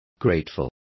Complete with pronunciation of the translation of grateful.